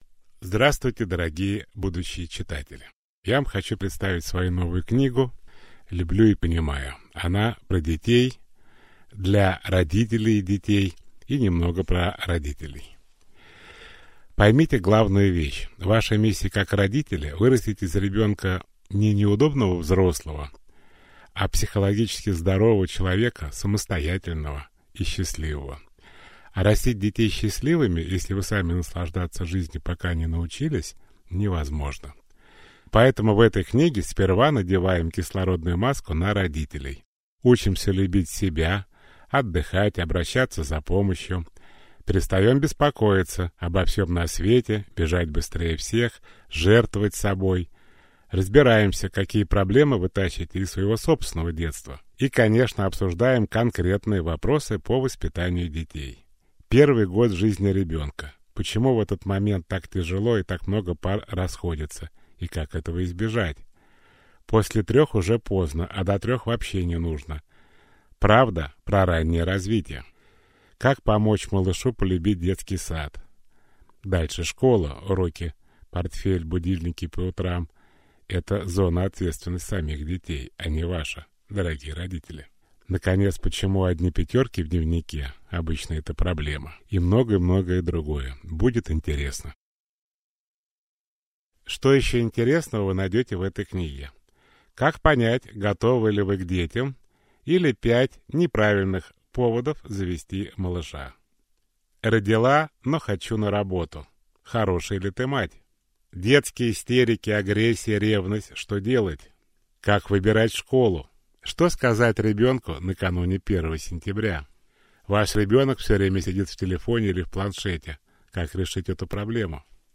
Аудиокнига Люблю и понимаю. Как растить детей счастливыми (и не сойти с ума от беспокойства) | Библиотека аудиокниг